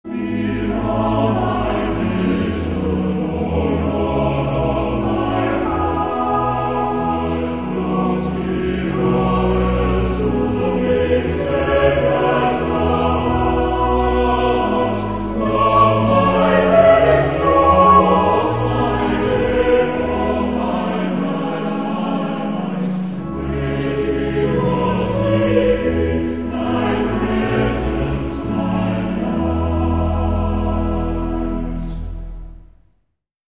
Trinity College Chapel
Choral, Classical, Organ